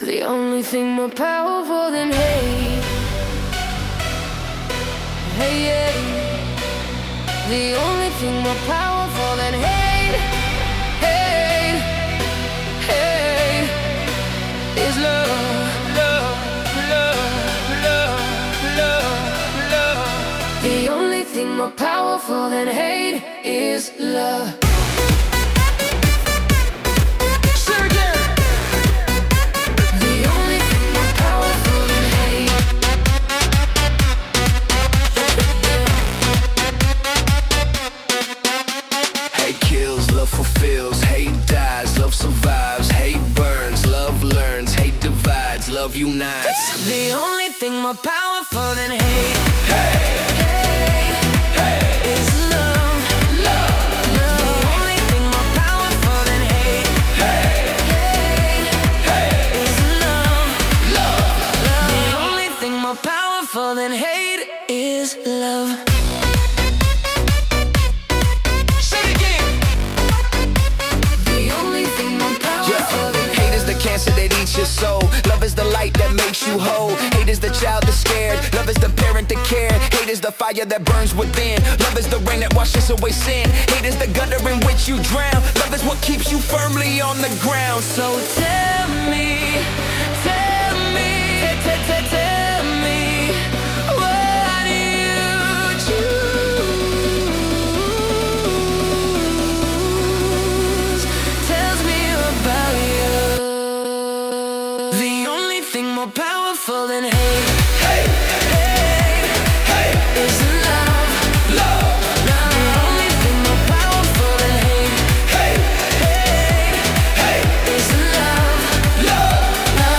Music/Vocals: AI Generated